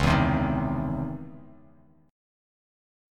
DbmM9 Chord
Listen to DbmM9 strummed